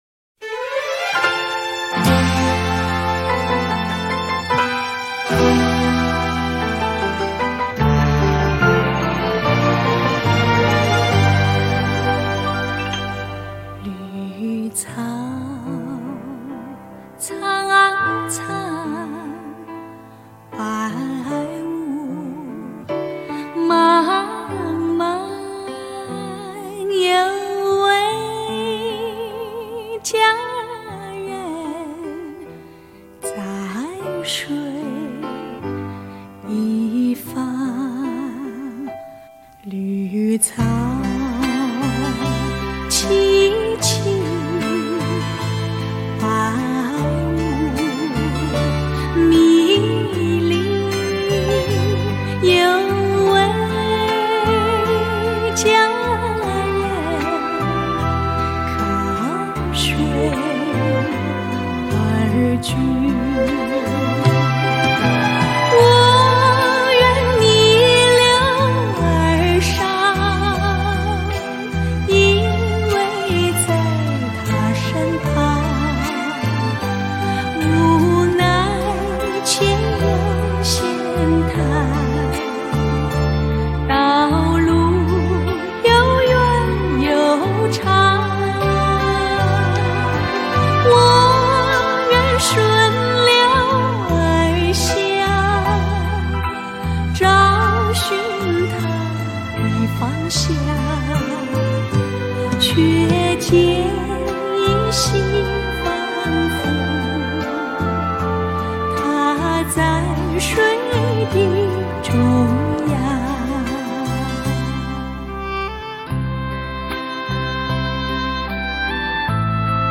经典歌曲